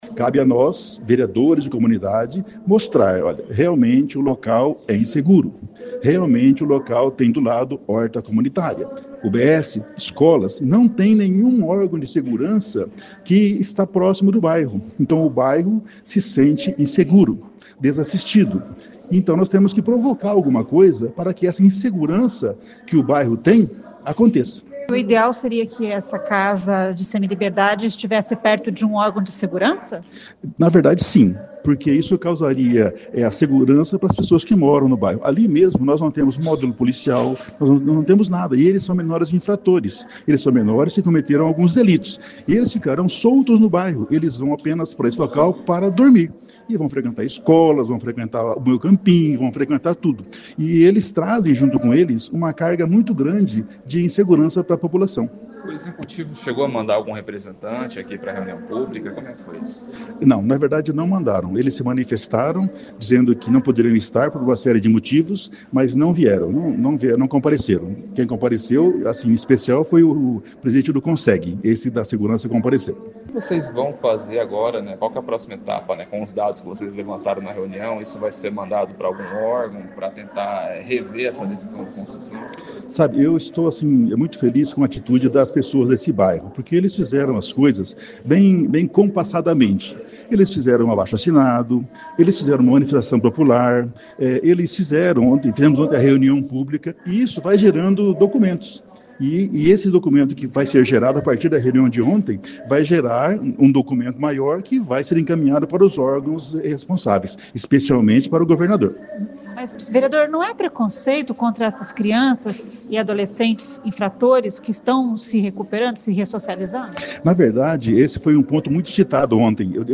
O vereador Professor Pacífico disse que a audiência foi importante para conhecer as razões que movem os moradores e a partir daí discutir o tema com toda a sociedade.